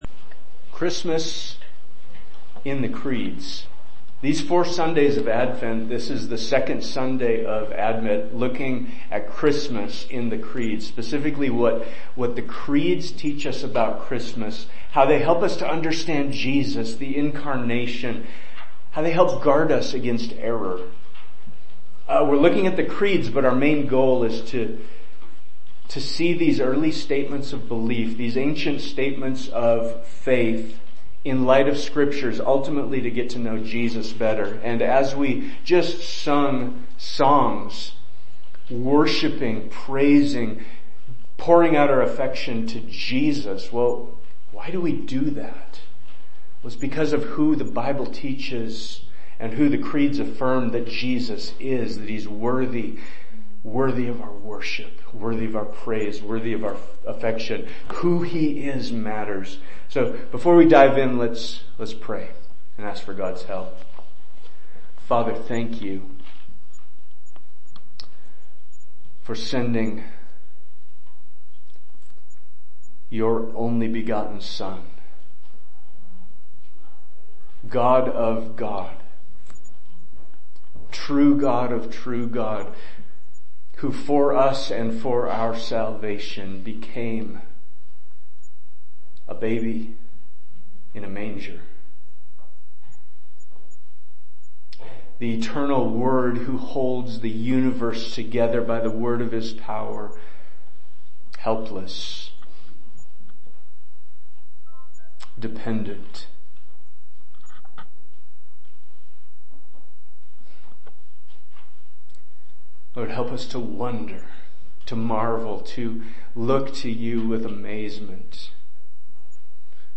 Preaching from the Pulpit of Ephraim Church of the Bible